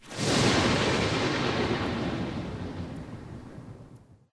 thunder2.ogg